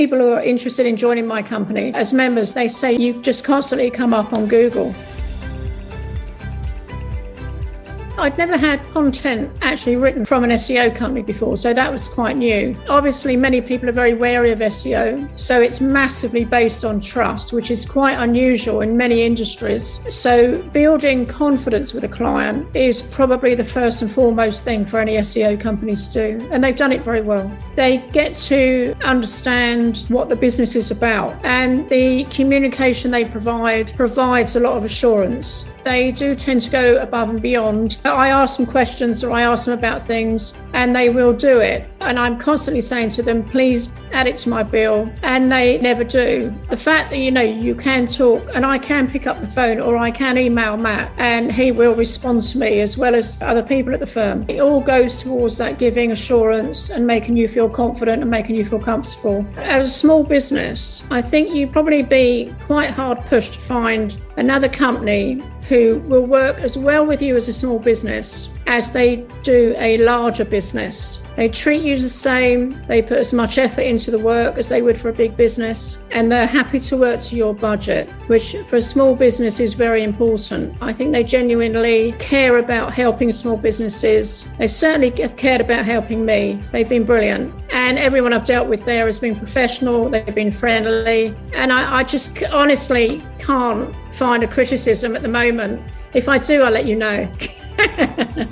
Decorative-Collective-Testimonial.mp3